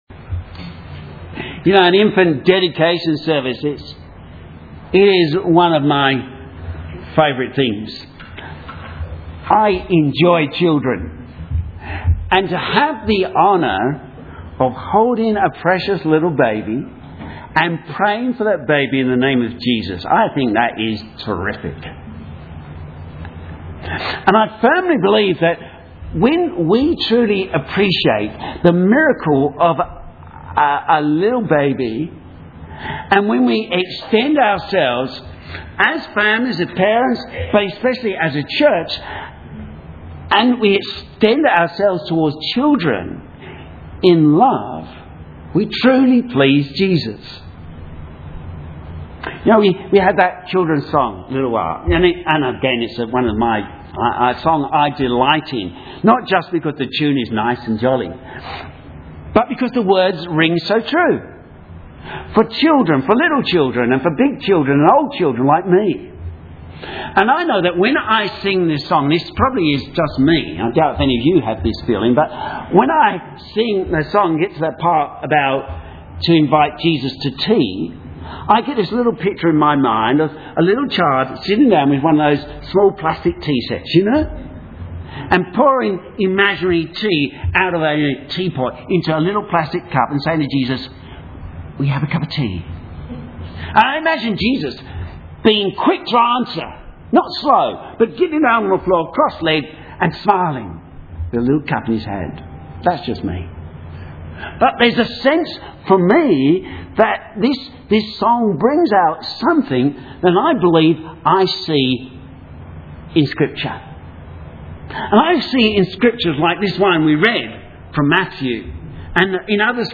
Sermon
Pointing Our Children Towards Our Father Matthew 18:1-11 Synopsis This address was given on the occasion of an infant dedication service and stresses the value of children in the sight of God, and how we need to know God as our father and then point our children towards Him Keywords Infant Dedication service.